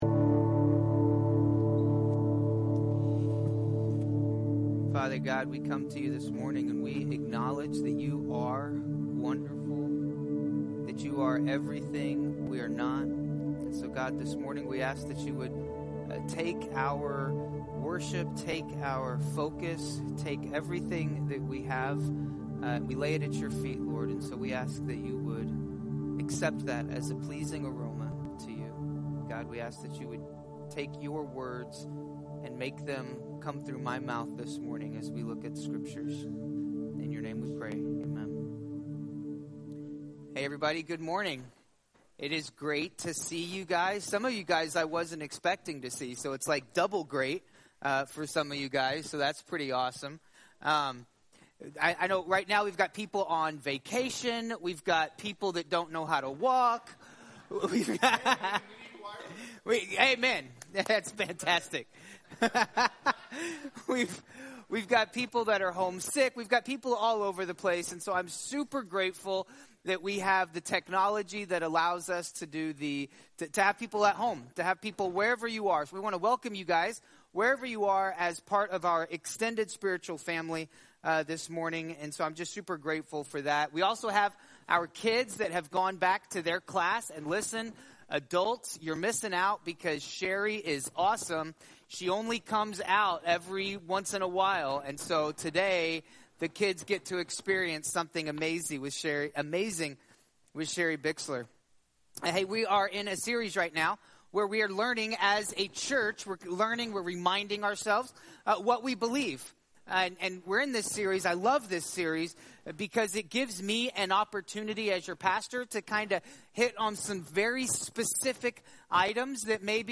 2023 Current Sermon I Believe